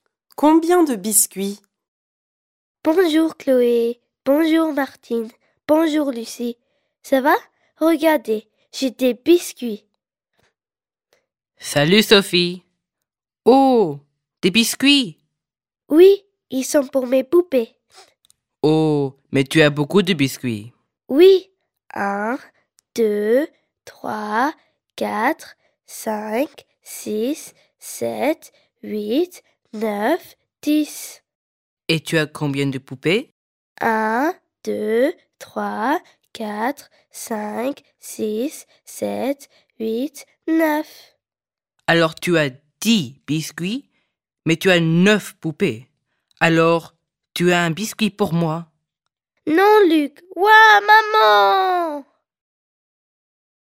Listen to the story 'Combien de biscuits' performed by native French speakers